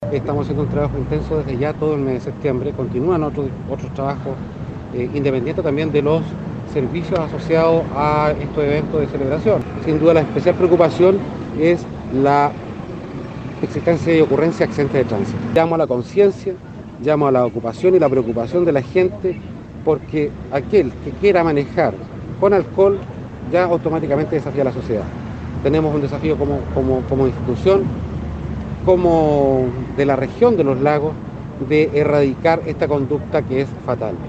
Para ello se contempla durante estos días una estricta fiscalización de parte del ministerio de Transportes a buses de recorrido provincial y regional, y también el despliegue de efectivos de Carabineros para el control carretero, como lo señaló el general Patricio Yáñez, jefe de la décima zona de Carabineros.